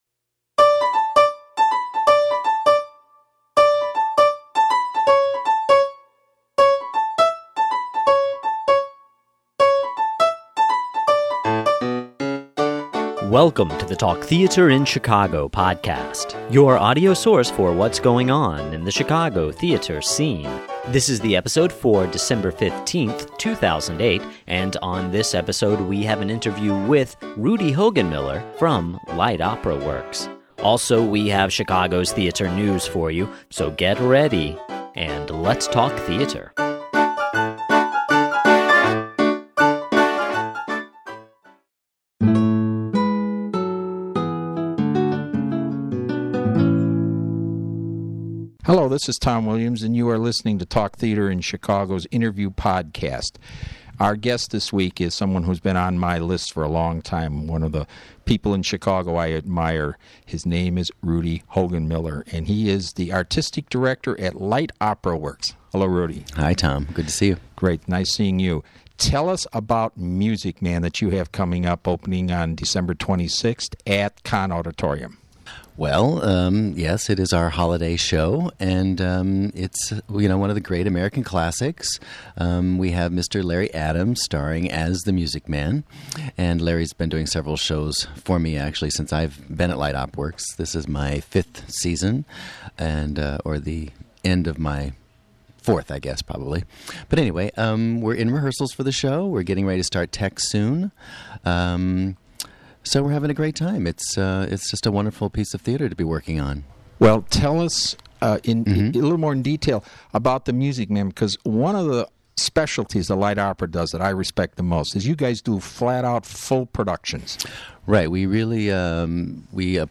Interview Podcast